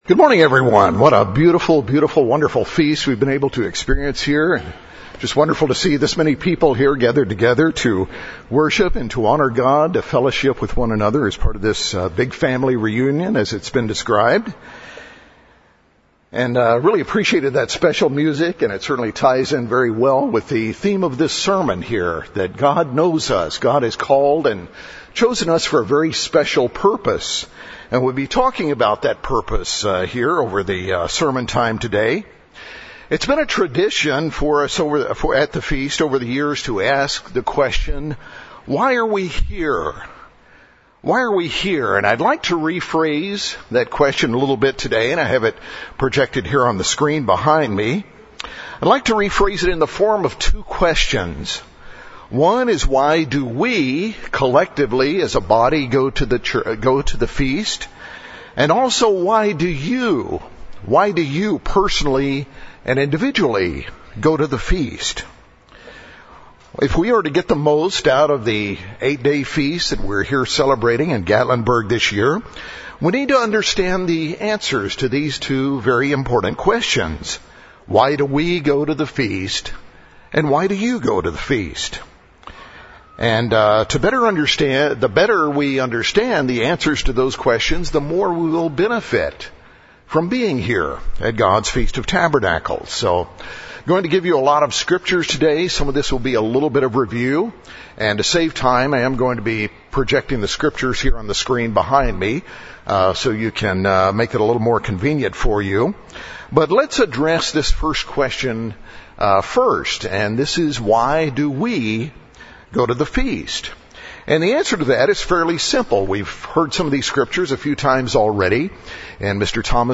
In this sermon we'll look at four specific reasons why God's people go to the Feast collectively, and three reasons why you go to the Feast individually.
This sermon was given at the Gatlinburg, Tennessee 2016 Feast site.